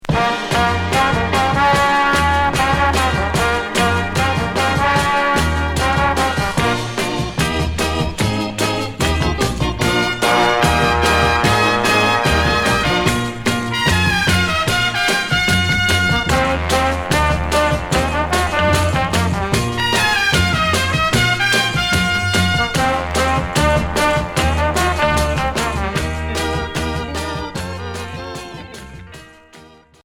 Jerk